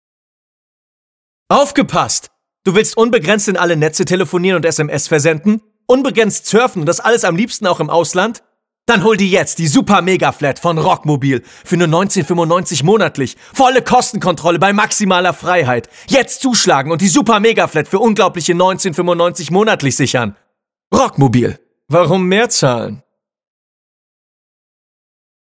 Vielseitig einsetzbare und wandlungsfähige Stimme – von wohlklingend bassig über jugendlich dynamisch bis hin zu Trickstimmen.
Sprechprobe: Werbung (Muttersprache):